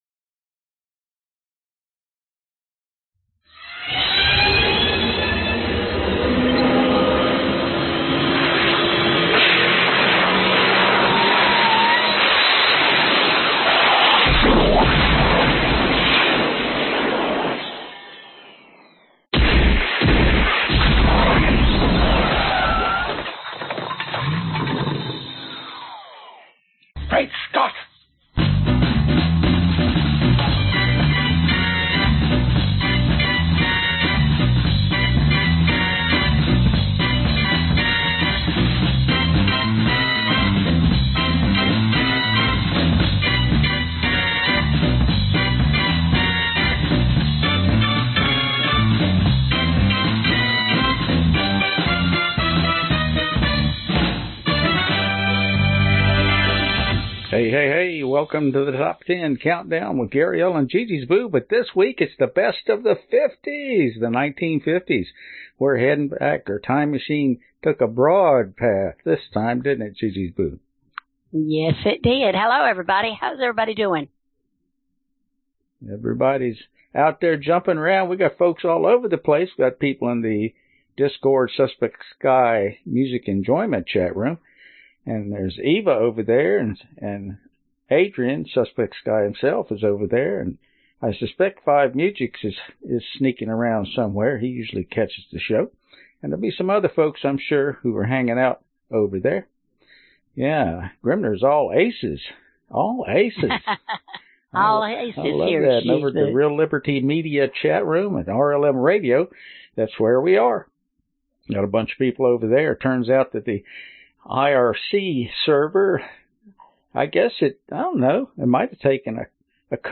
Genre Oldies